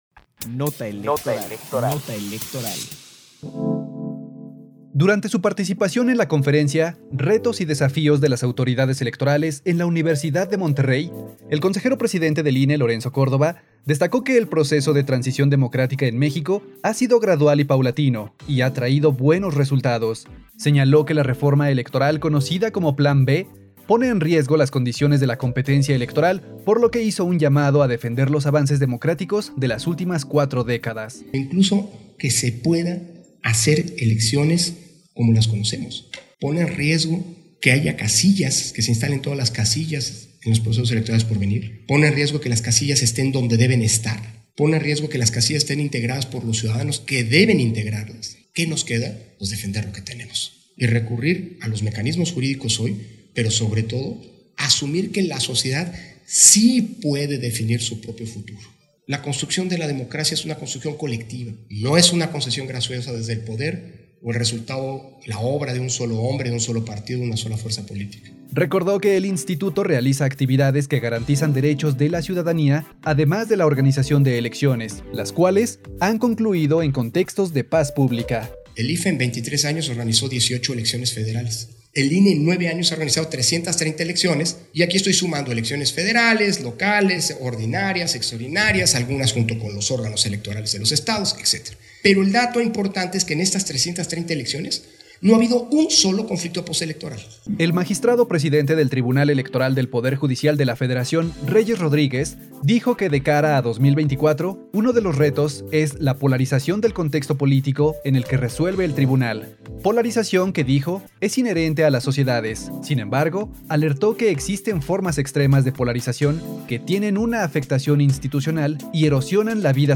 PD_1397_NOTA ELEC_LCV CONFERENCIA UNIVERSIDAD DE MONTERREY 31 enero 2023_CE - Central Electoral